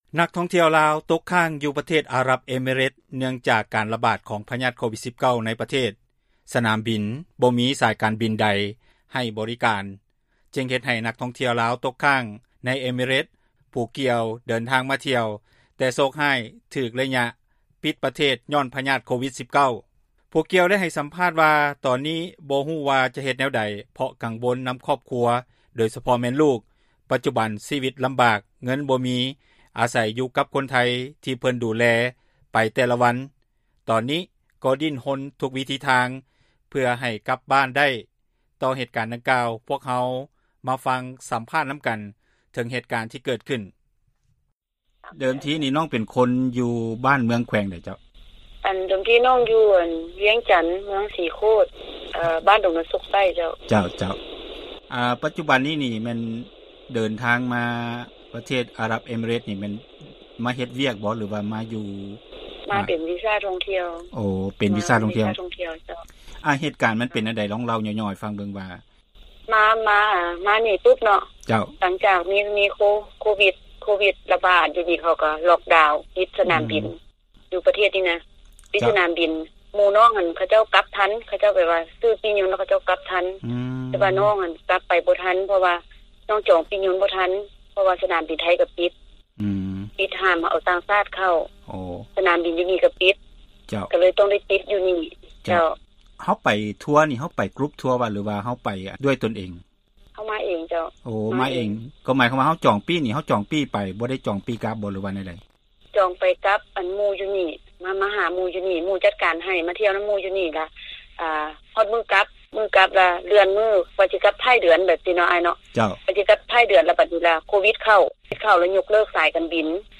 ຕໍ່ເຫດການດັ່ງກ່າວພວກເຮົາມາຟັງ ສັມພາດ ນຳກັນເຖີງເຫດການ ທີ່ເກີດຂຶ້ນ.